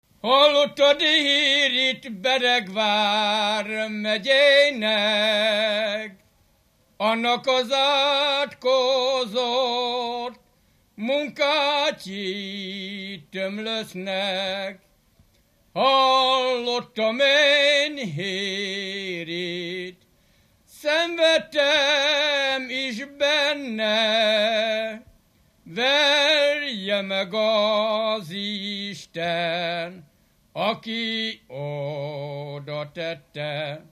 Alföld - Hajdú vm. - Hajdúböszörmény
ének
Stílus: 4. Sirató stílusú dallamok
Kadencia: 5 (4) 5 1